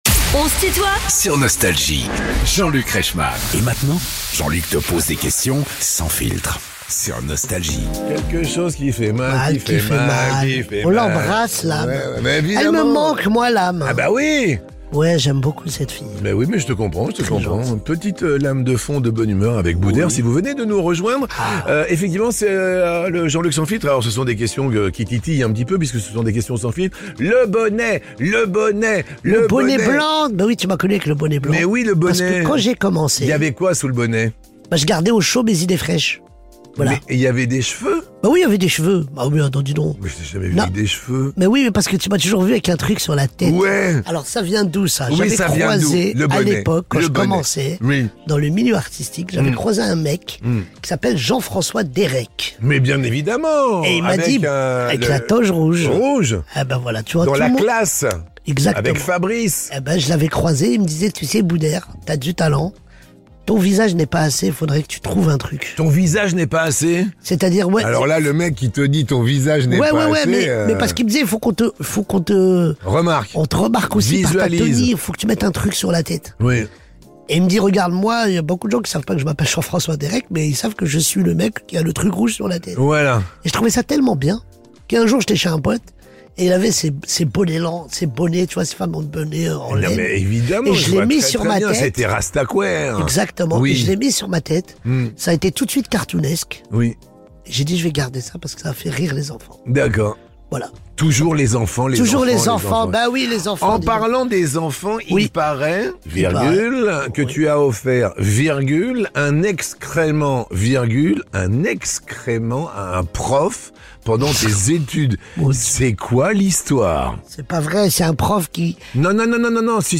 Invité de "On se tutoie ?...", Booder répond aux questions sans filtre de Jean-Luc Reichmann ~ Les interviews Podcast